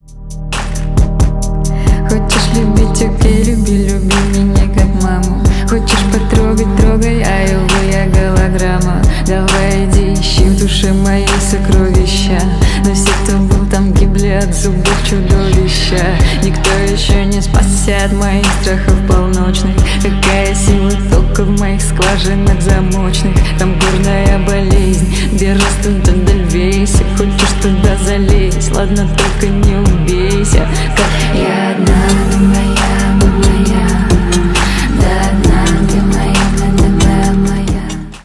инди